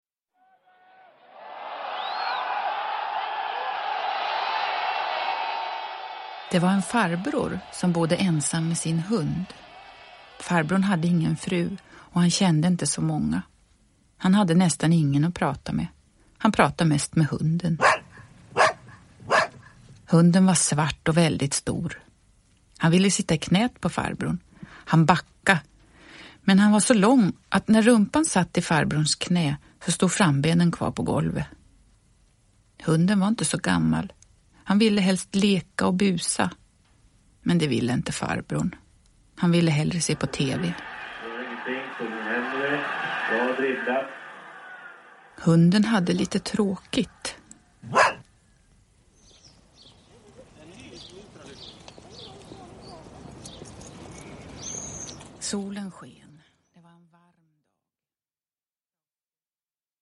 Dagspöket kommer hem – Ljudbok – Laddas ner
Uppläsare: Jujja Wieslander